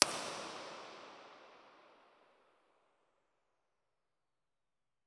Here are the RIRs for the PL-11.
Test Position 1 – 20 ft
The Tectonic produced significantly more room excitation than the other devices, including the reference Dodecahedron loudspeaker.
Tectonic20ftIR.wav